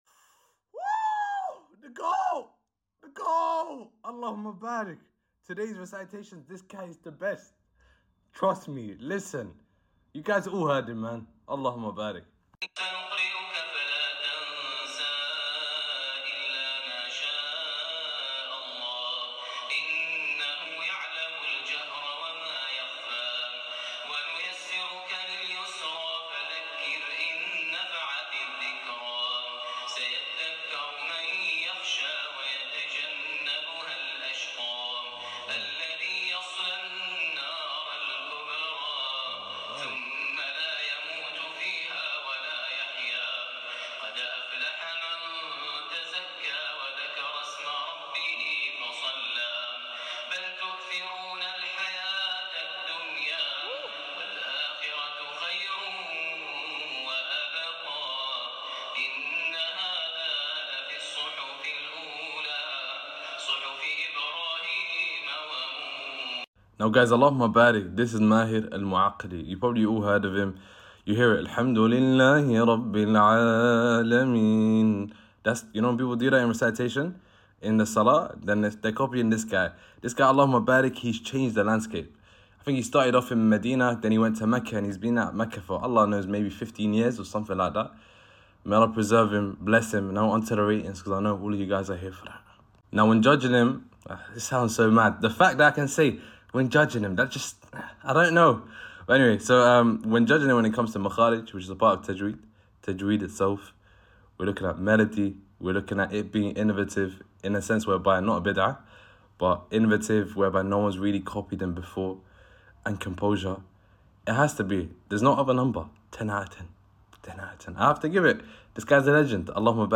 This is a classic from the recordings of the imam of the sacred mosque. This Quran recitation genuinely amazing to hear and is the recording of Maher al mueaqly. It comes with a load of tajweed.